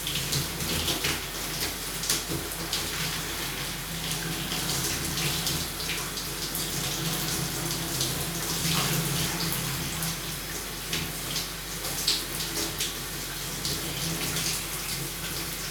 water_running_shower_dripping_loop_01.wav